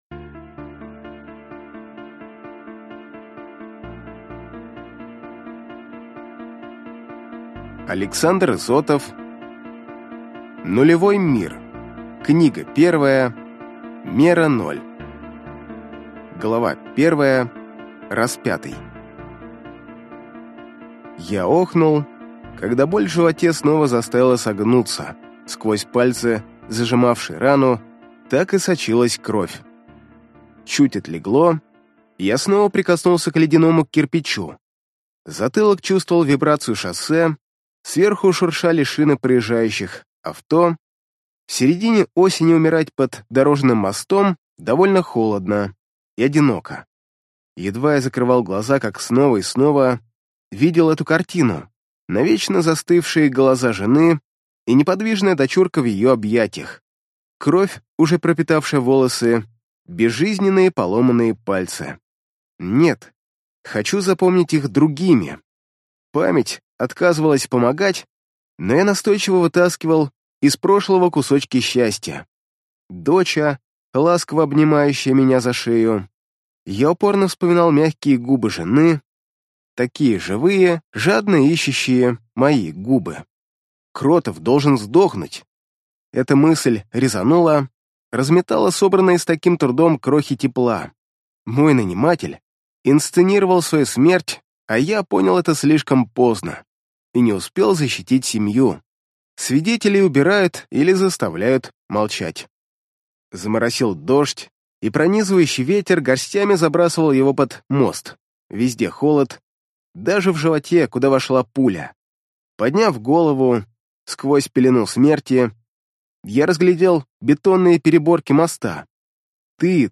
Аудиокнига Нулевой мир. Книга 1. Мера ноль | Библиотека аудиокниг